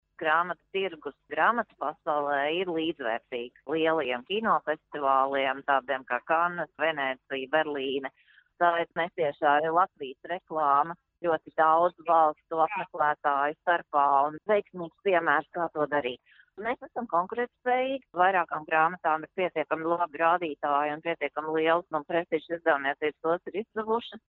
no Frankfurtes grāmatu gadatirgus